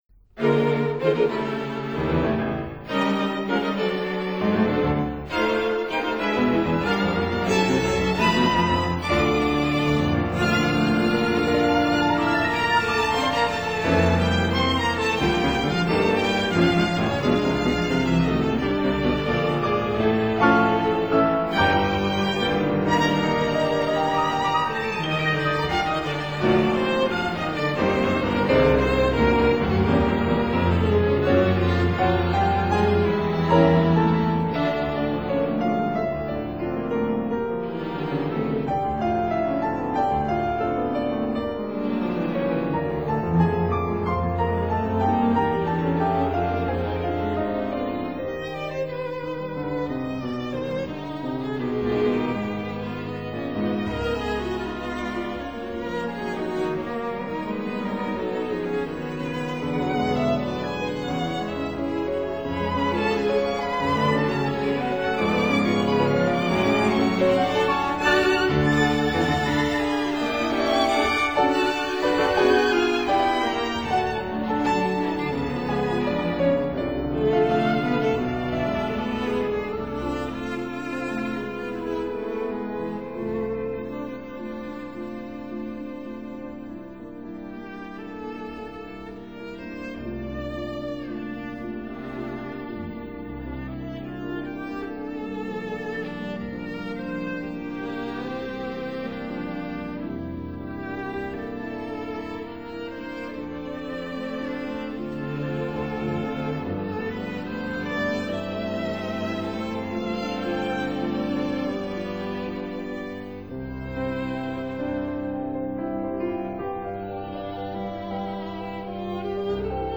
piano
violins
viola
cello